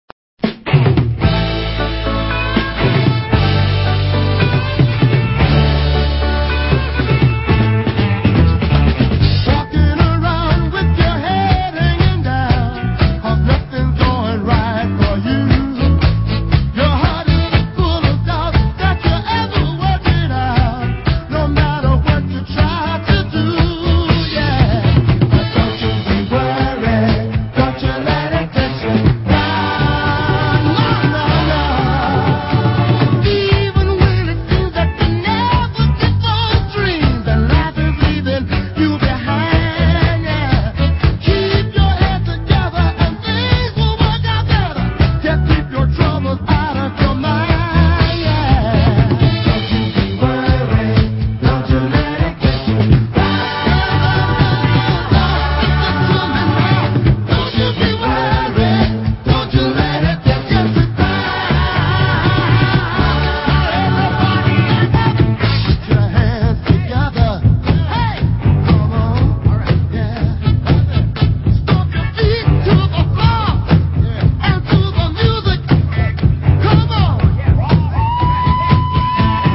Early pounding dancer from this classic group